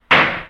23 Golpe contra maderaconsolidated